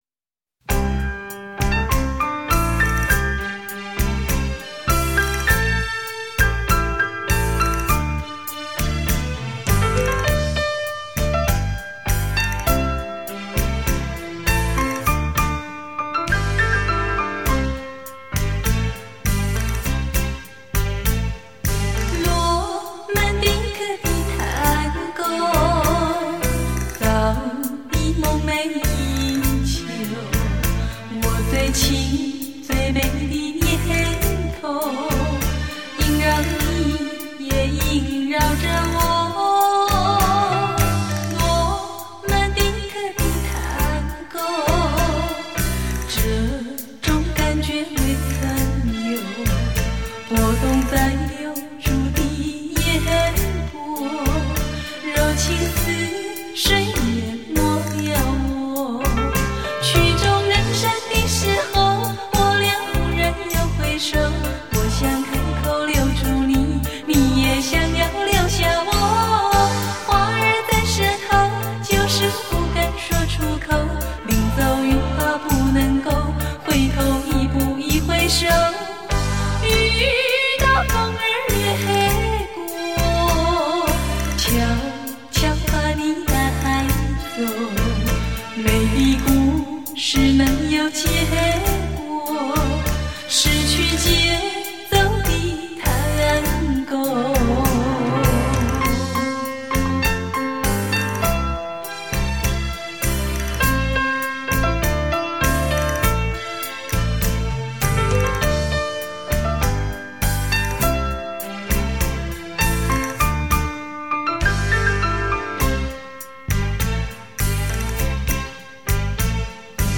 最经典的华丽风格 最熟悉的国语老歌